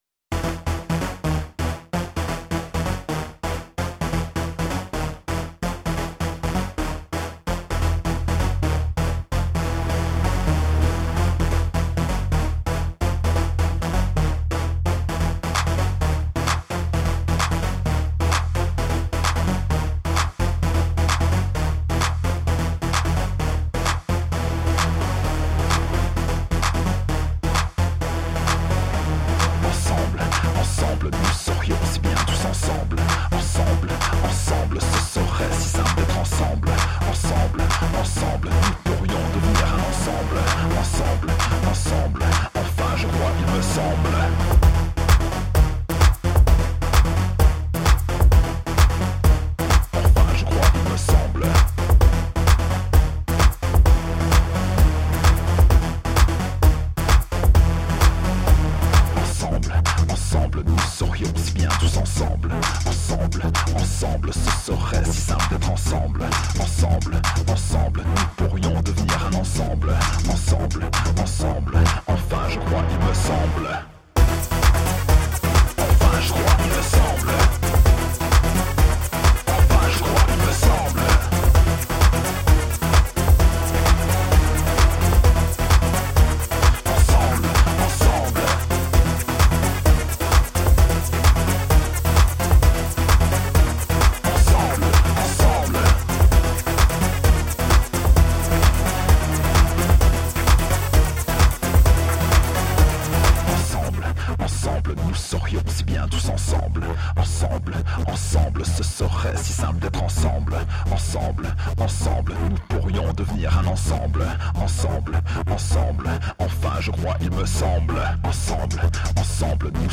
Energetic electro rock, rediscovering and recreating raves.